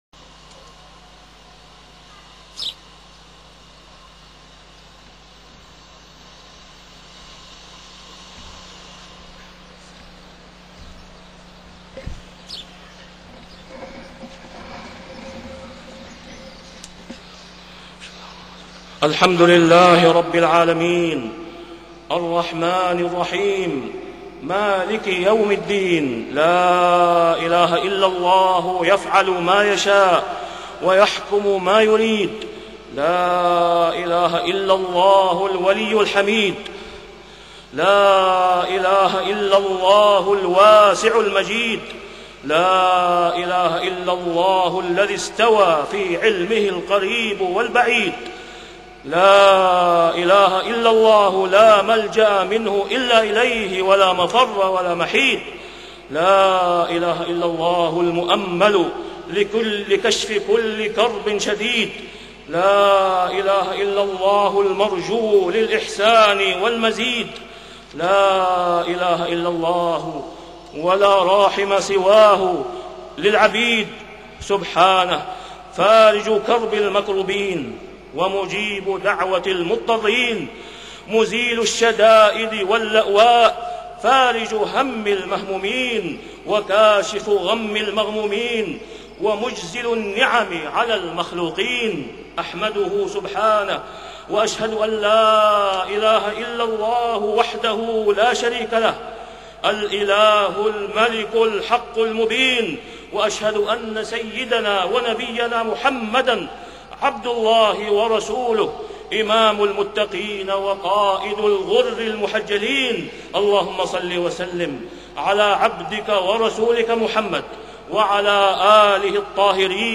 خطبة الاستسقاء 23 صفر 1430هـ > خطب الاستسقاء 🕋 > المزيد - تلاوات الحرمين